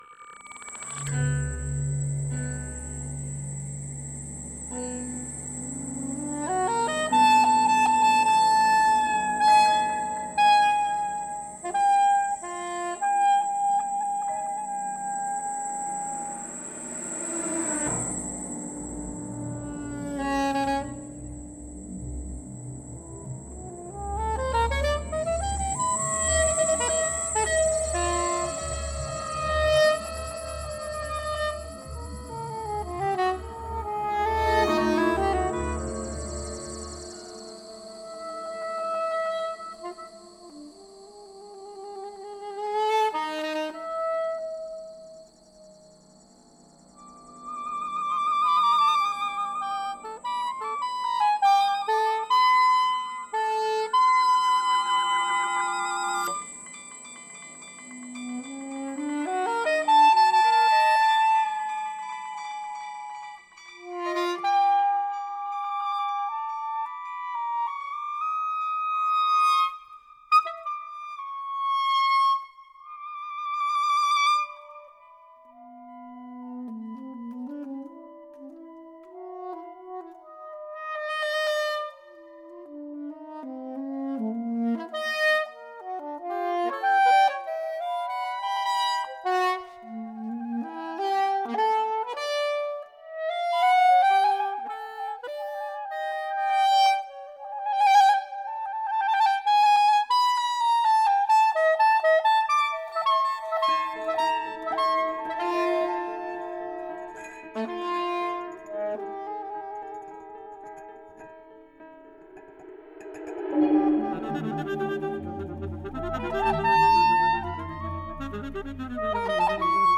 For soprano, saxophone and electronics